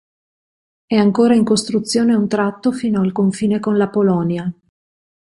Po‧lò‧nia
/poˈlɔ.nja/